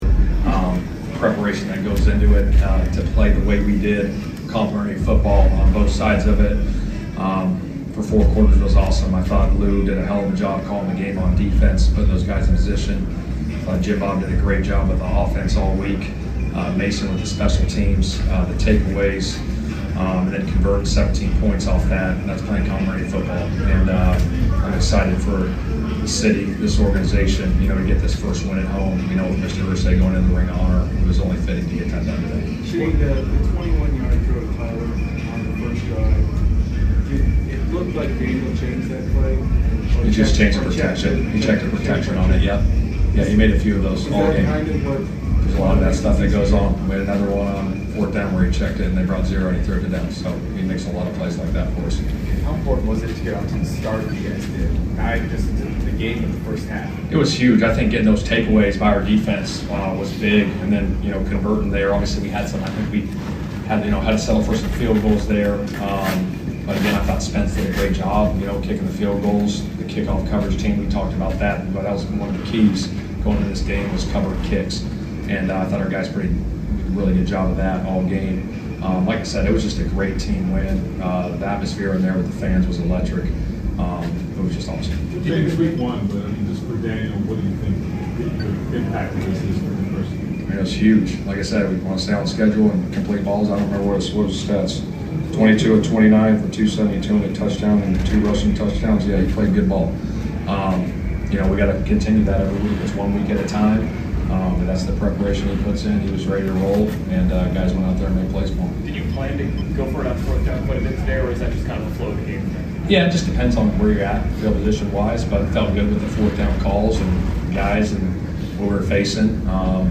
Indianapolis Colts Coach Shane Steichen Postgame Interview after defeating the Miami Dolphins at Lucas Oil Stadium.